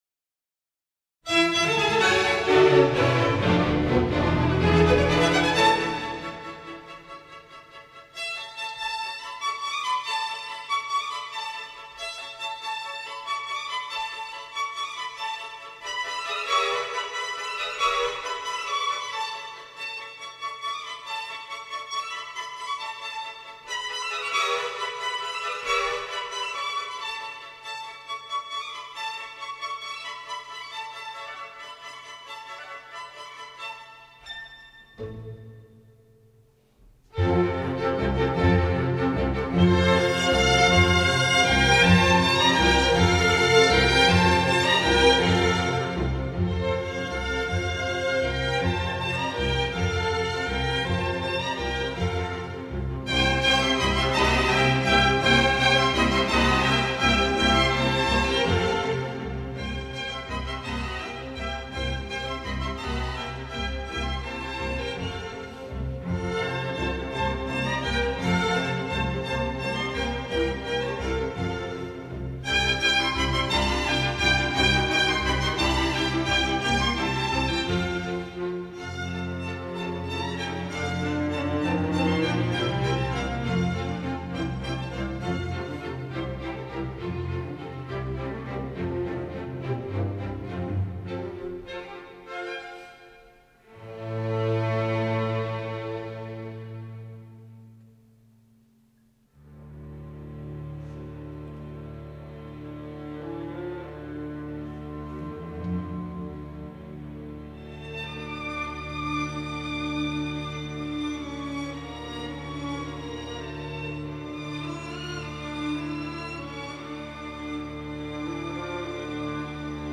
Orchestre de chambre